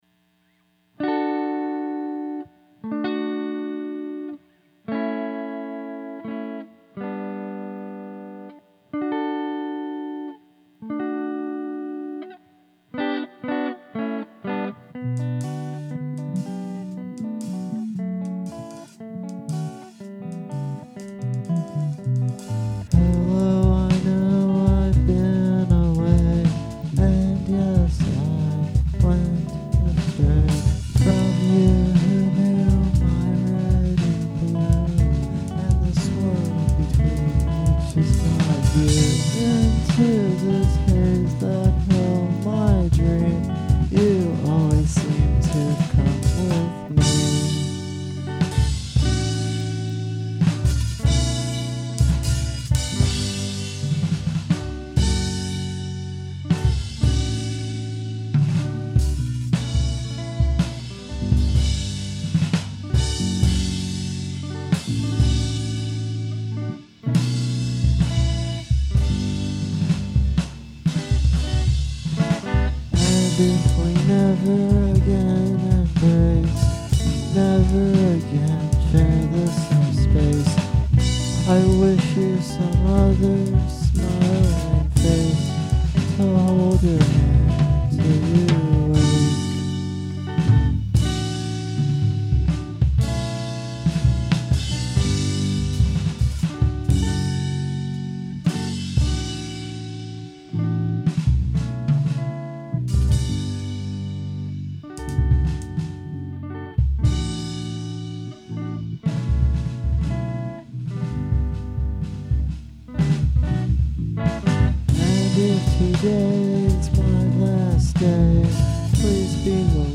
So heres another... i had a little help from too angels who came back too sing the last part for me. Edit: all "respect" and thanks too radio shack for allowing me too by a mic for $15; fair warning haha.